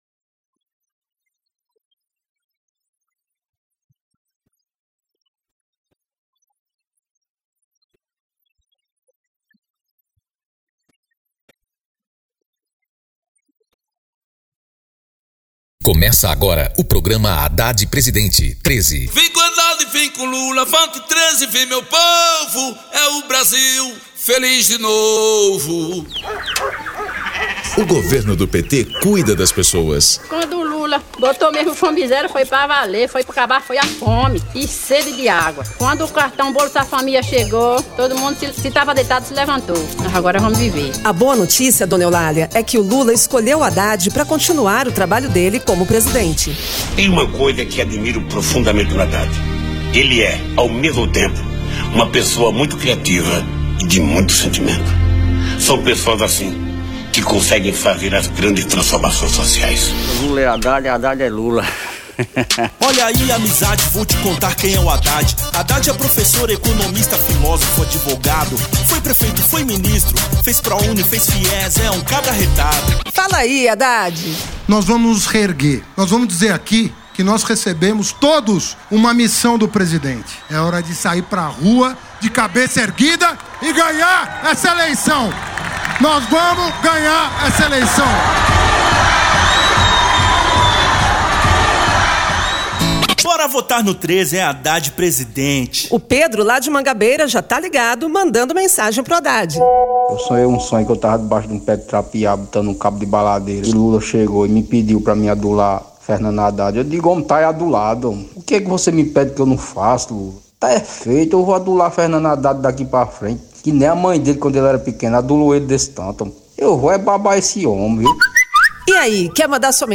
Gênero documentaldocumento sonoro
Descrição Programa de rádio da campanha de 2018 (edição 13) - 1° turno